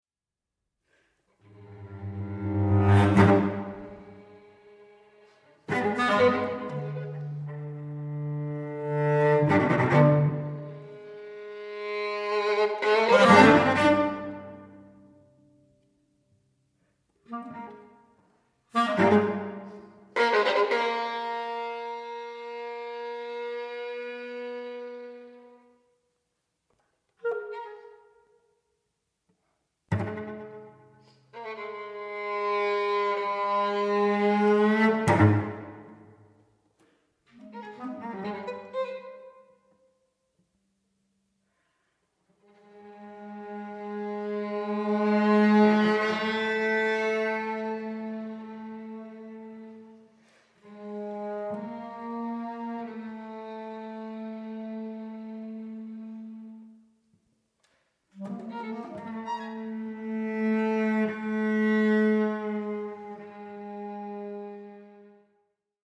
• Genres: Classical, Opera, Chamber Music
Recorded at Evelyn & Mo Ostin Music Center